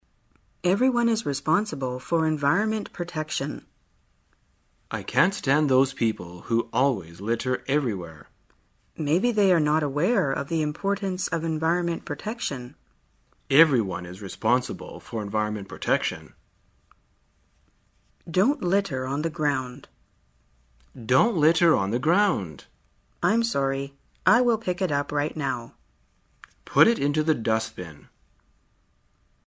在线英语听力室生活口语天天说 第14期:怎样谈论环境卫生的听力文件下载,《生活口语天天说》栏目将日常生活中最常用到的口语句型进行收集和重点讲解。真人发音配字幕帮助英语爱好者们练习听力并进行口语跟读。